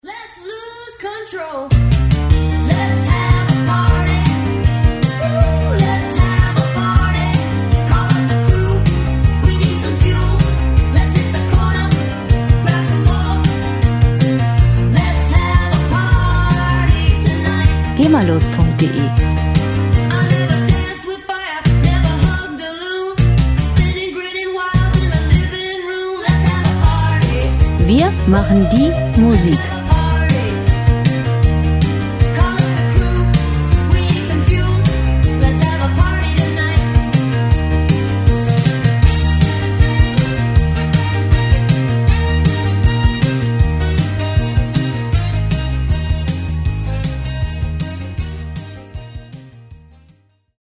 Musikstil: Rockabilly
Tempo: 157 bpm
Tonart: Es-Dur
Charakter: unverfälscht, ungebändigt